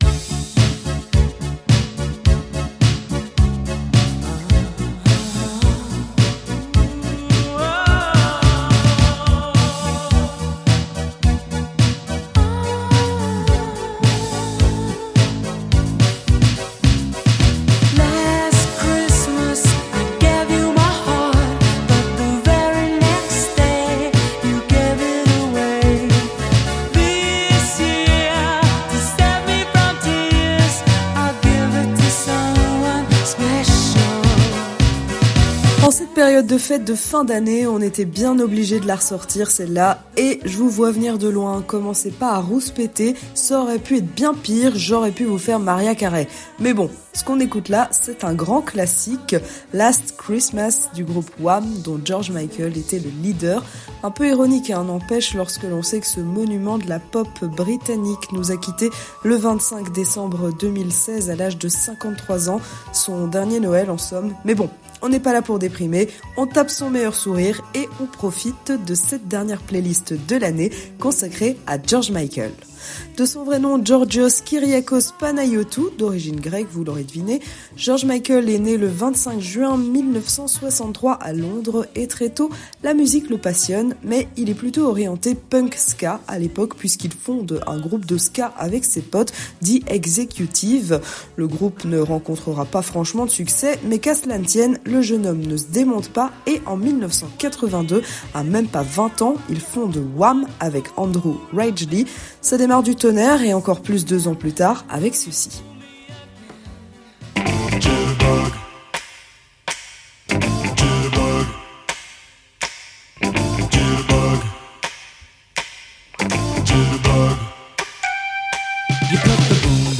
Une chronique imaginée